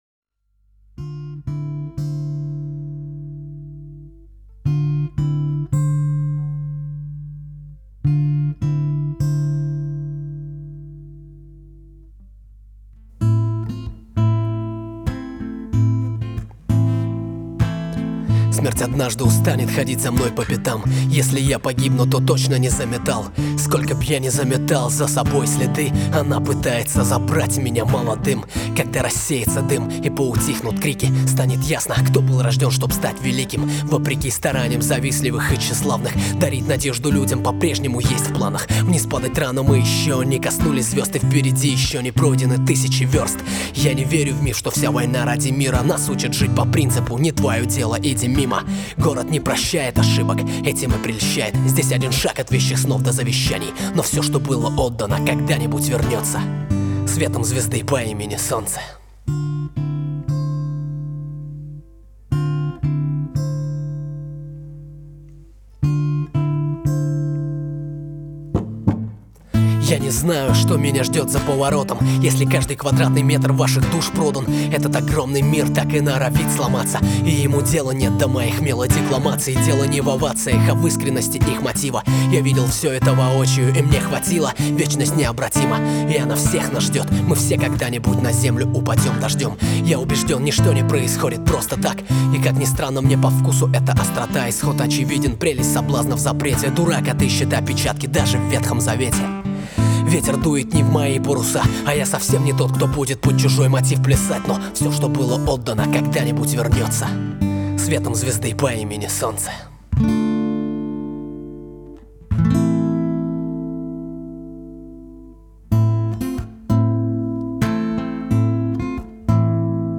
Категория: Красивая музыка » Песни под гитару